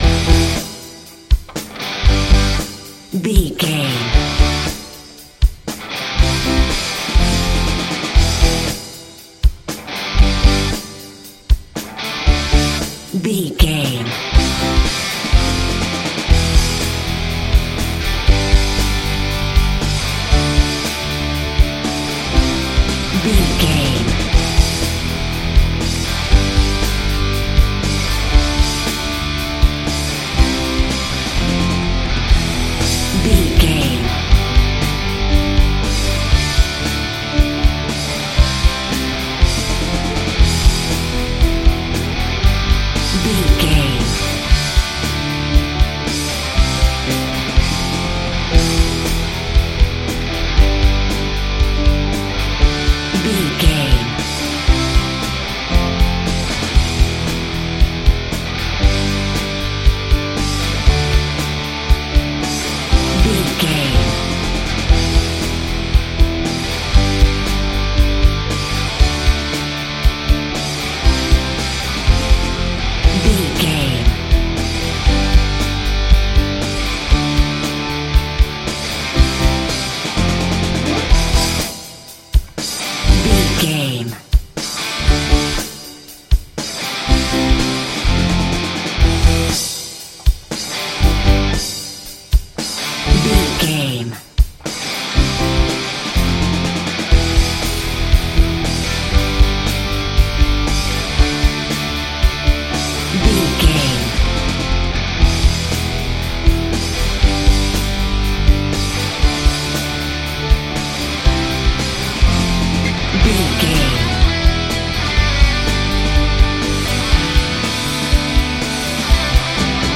Ionian/Major
electric guitar
bass guitar
drums
pop rock
hard rock
lead guitar
aggressive
energetic
intense
powerful
nu metal
alternative metal